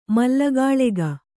♪ mallagāḷega